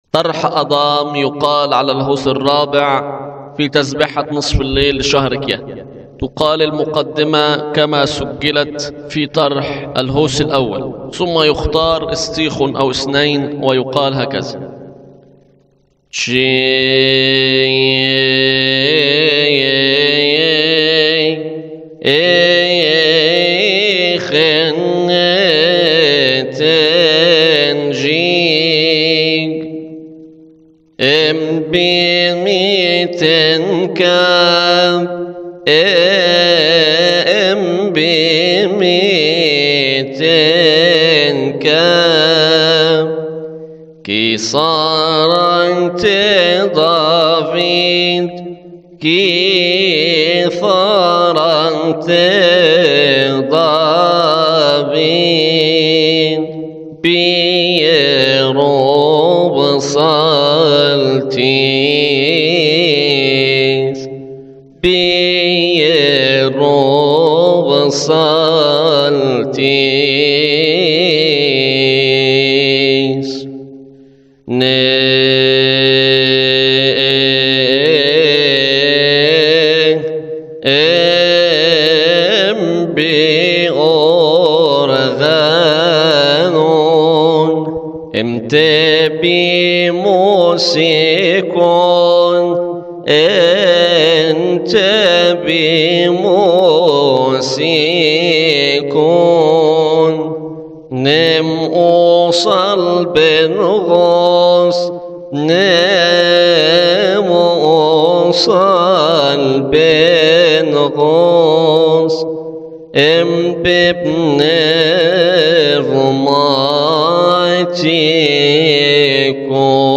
مكتبة الألحان
طرح آدام علي الهوس الرابع يقال في تسبحة نصف الليل بشهر كيهك